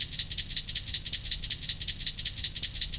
Tambo_Loop_C_160.wav